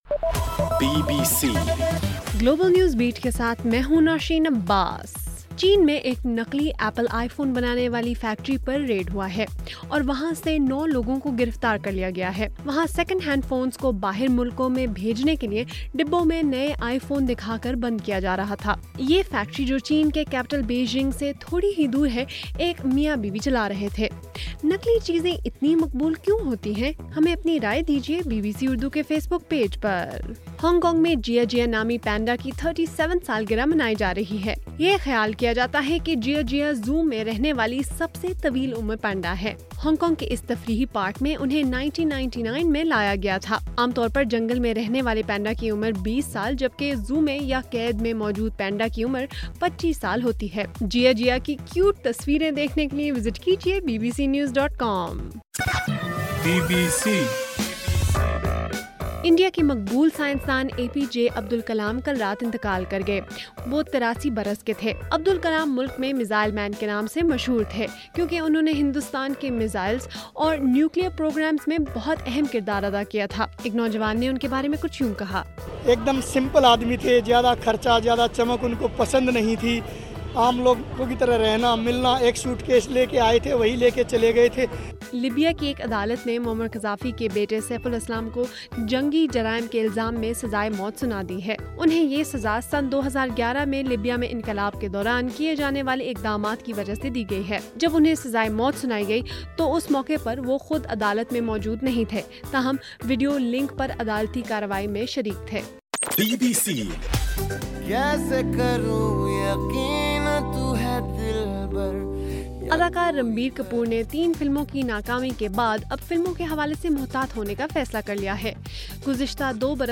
جولائی 28: رات 8 بجے کا گلوبل نیوز بیٹ بُلیٹن